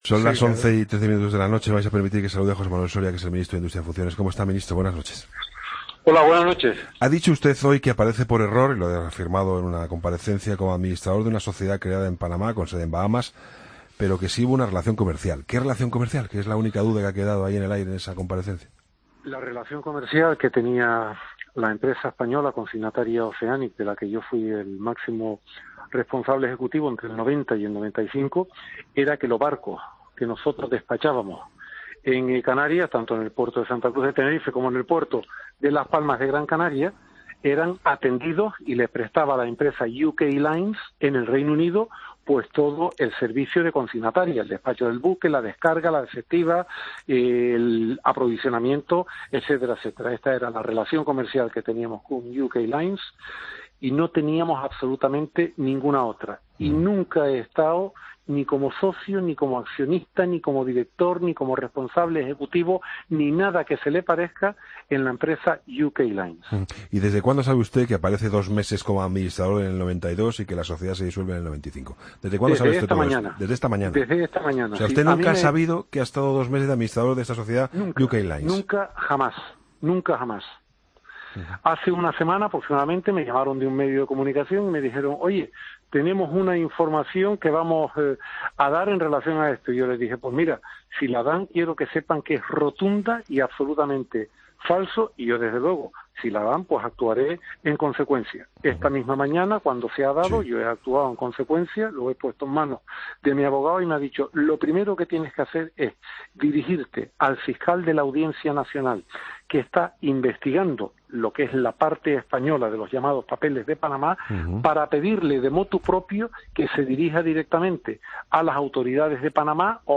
Escucha la entrevista al ministro de Industria, Energía y Turismo en funciones, José Manuel Soria, en 'La Linterna'